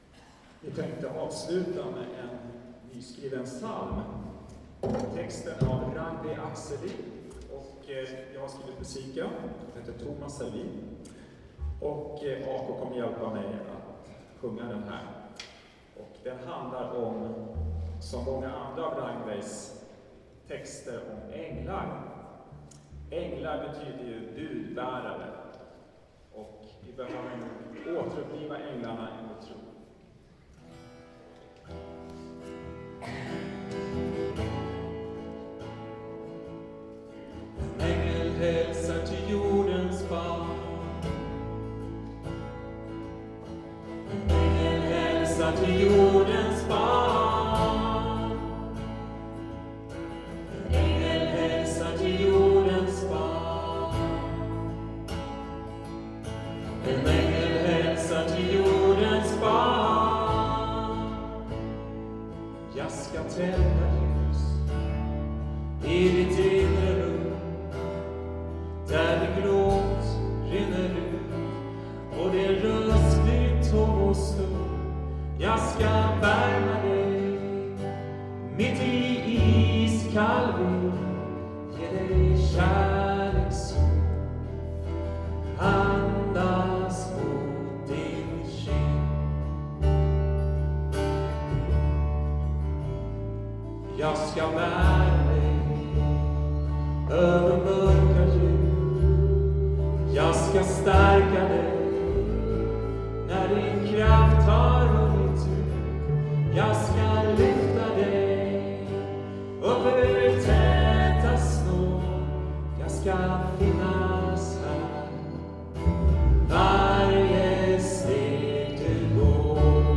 Kontrabas